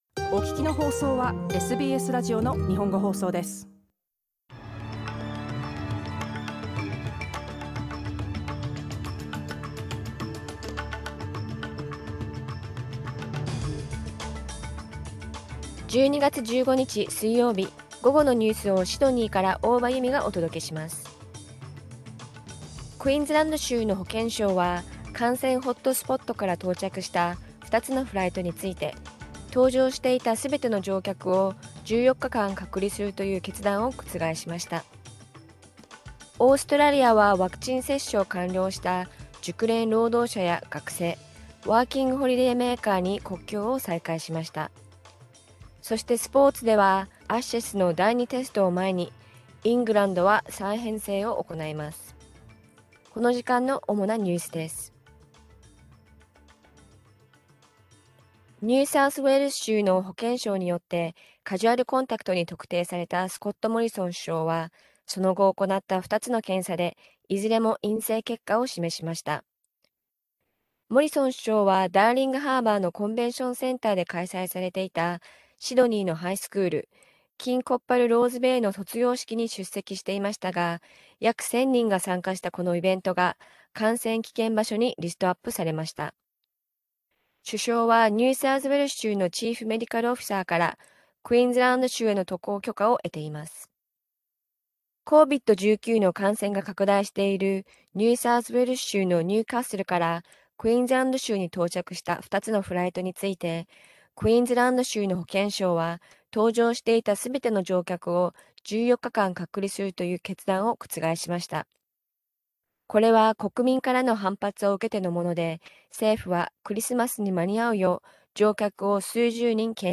Afternoon news in Japanese, 15 December 2021